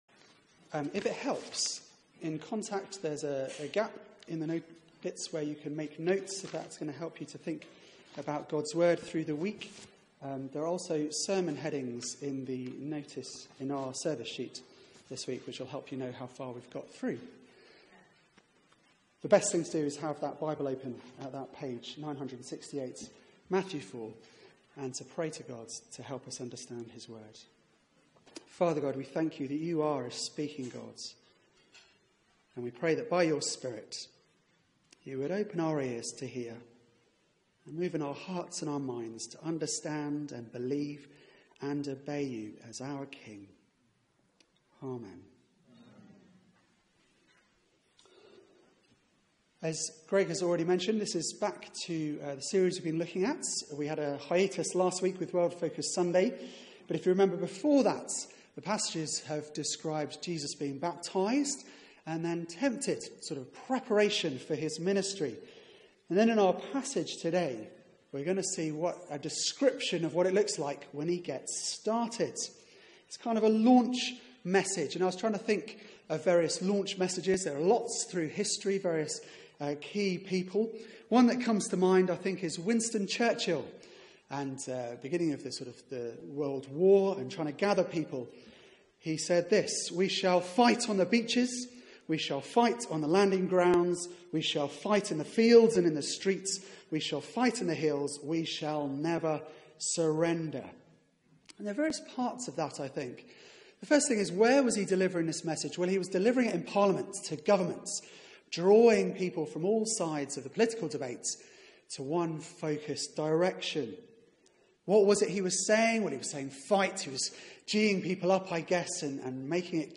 Media for 4pm Service on Sun 05th Feb 2017 16:00 Speaker
Passage: Matt 4:12-17 Series: Following Jesus Theme: Message of Jesus Sermon